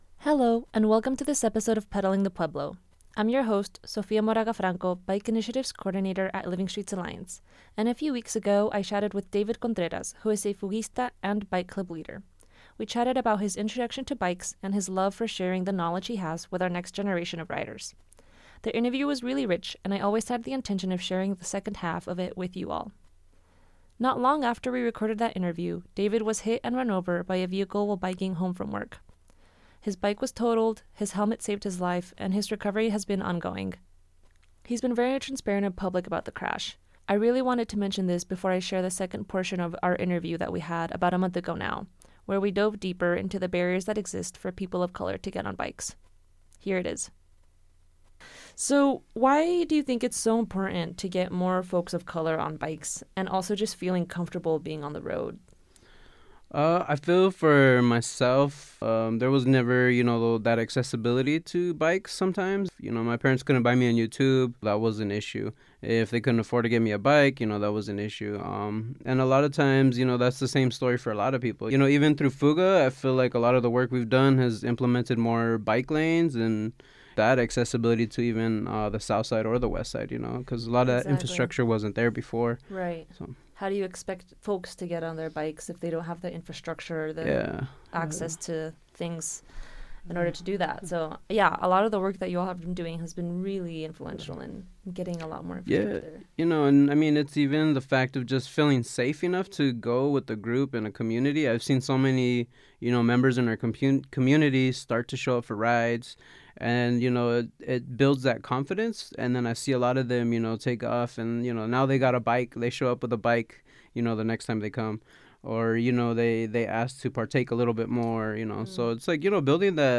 The interview was really rich, and I always had the intention of sharing the second half of it with you all.